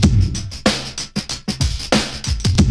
BEAT 11 940A.wav